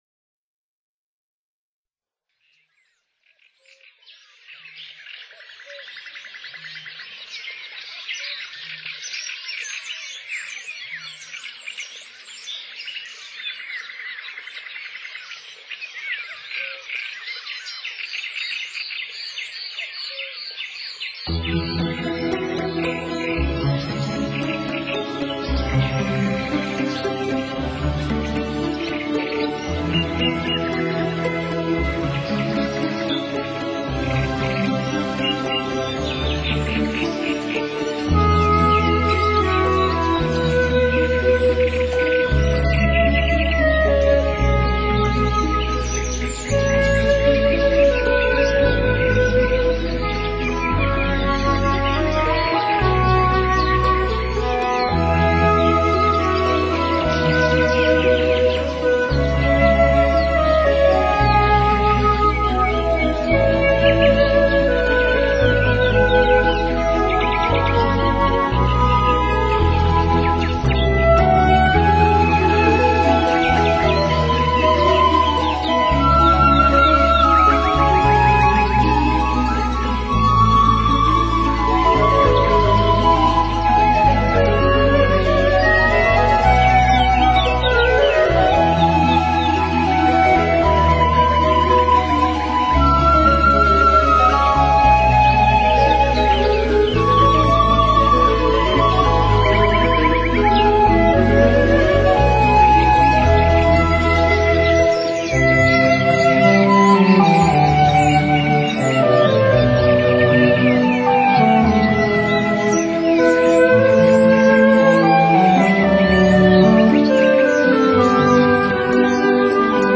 海浪、流水、鸟鸣，风吹过树叶，雨打在屋顶，大自然的原始采样加上改编的著名乐曲合成了天籁之音。